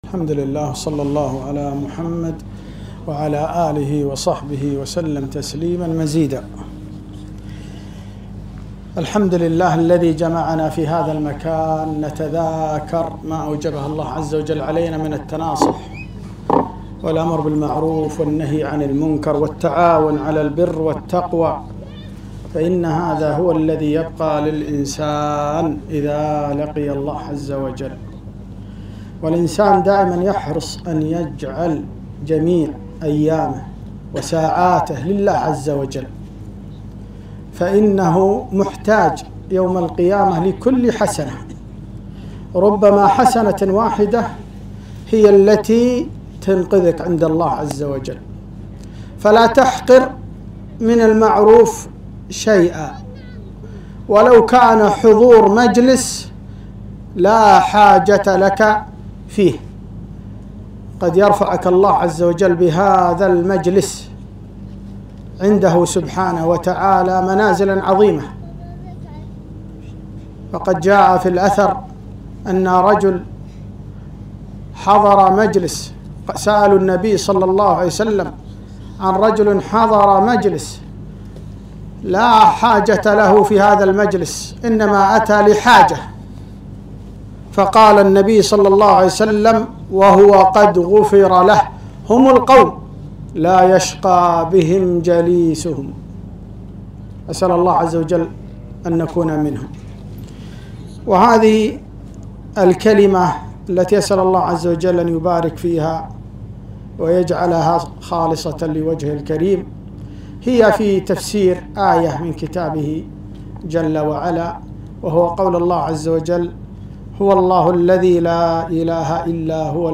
محاضرة - وقفات مع آية ( هو الله الذي لا إله إلا هو الملك)